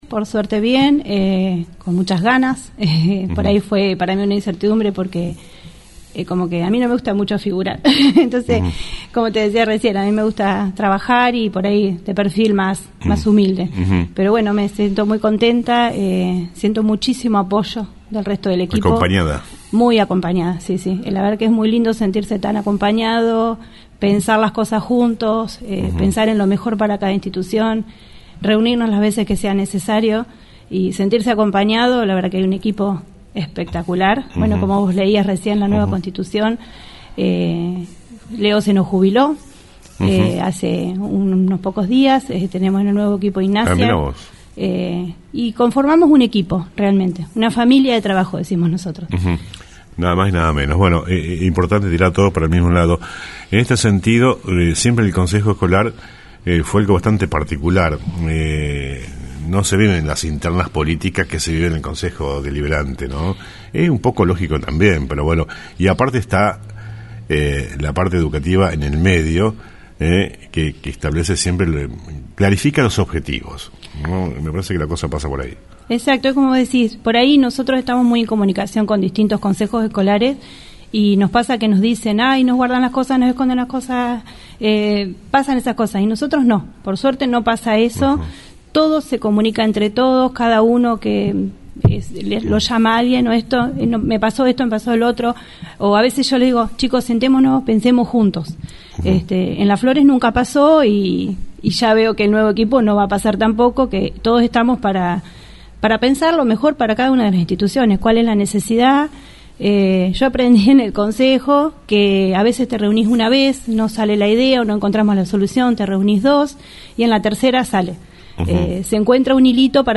En la nueva conformación del consejo escolar de nuestra ciudad, fue elegida Pta. La docente Maricel Renero, es por esto que la entrevistamos en “el periodístico” del día de la fecha.